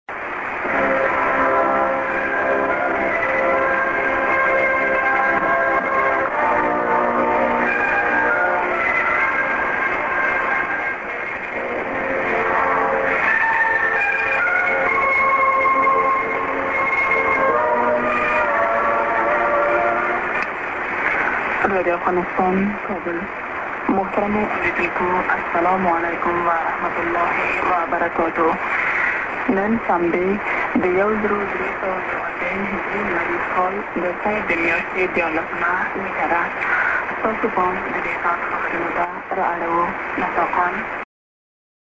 music->ID(women)->prog